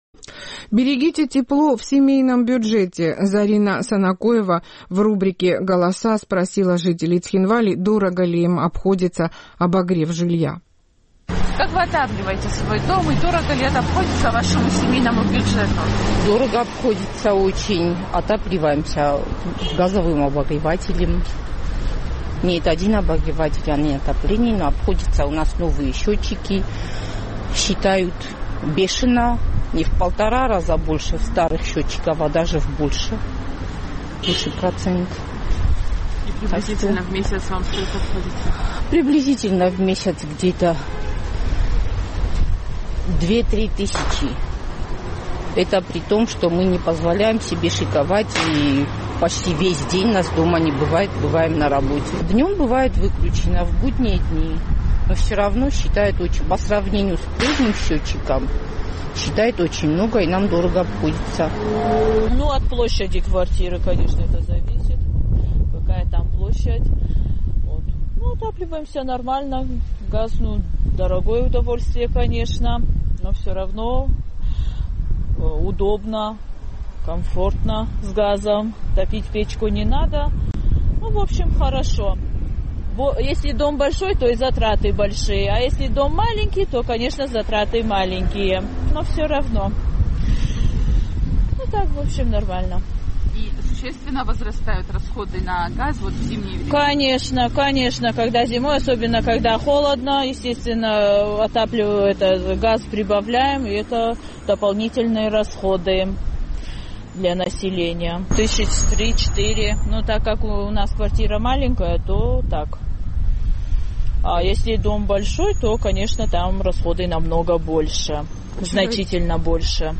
Наш цхинвальский корреспондент поинтересовалась у местных жителей, как они отапливают свой дом зимой и дорого ли это обходится их семейному бюджету.